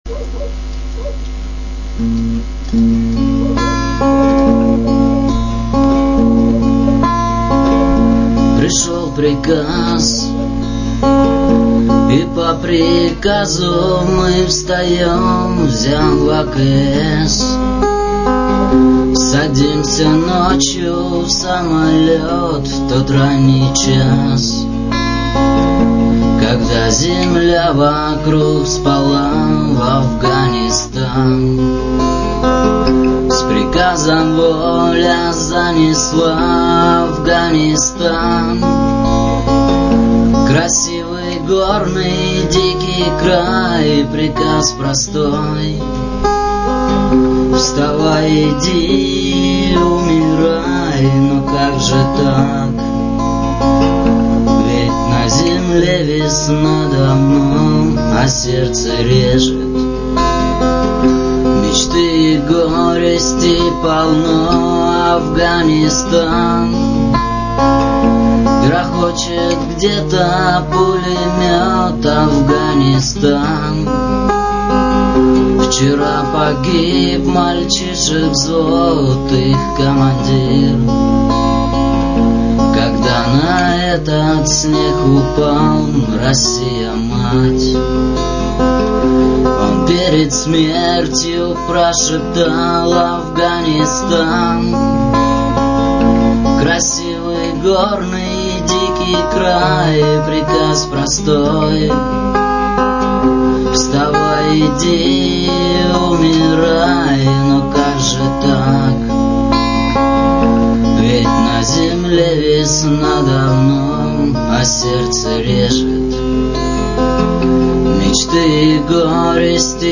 Главная » Файлы » Песни под гитару » Песни у костра
Песни у костра [44]